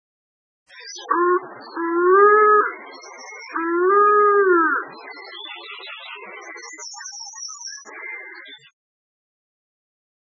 〔カラスバト〕ウッウー，ウッウー／本州中部以南の海岸地域などで見られる，稀・留